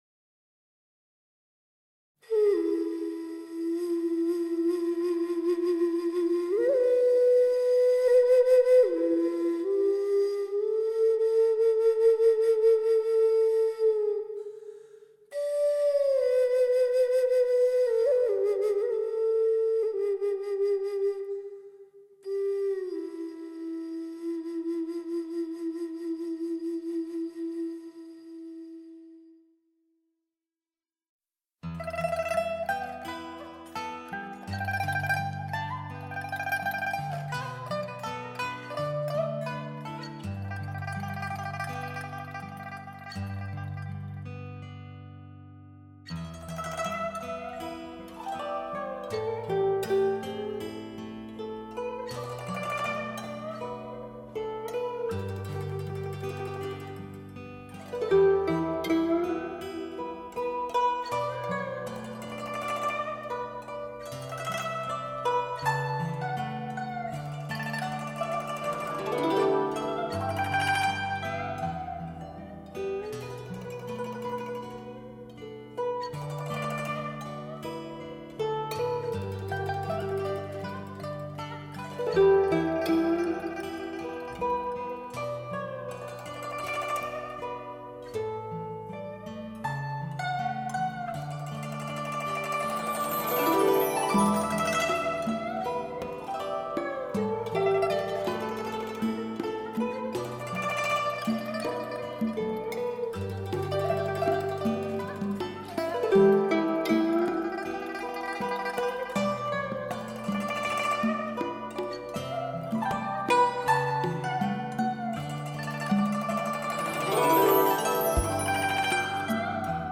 采用最新盖DTS-ES6.1顶级编码器
创造超乎想象完美环绕声体验